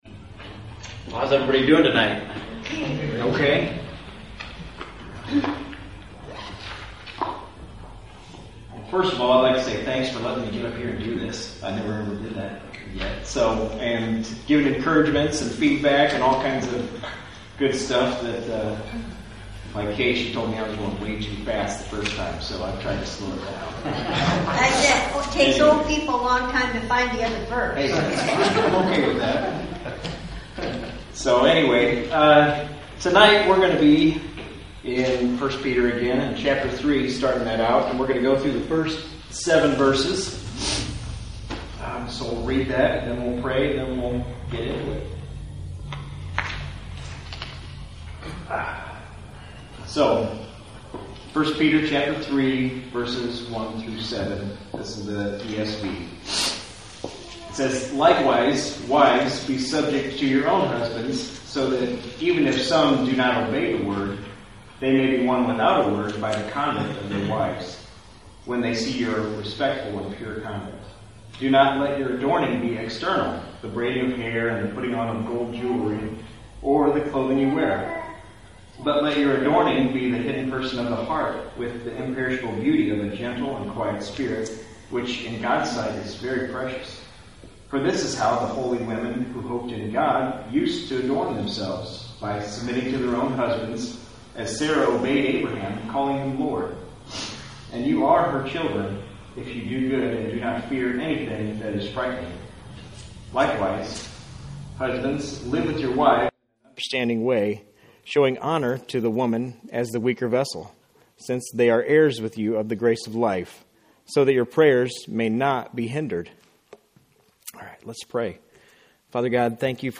A teaching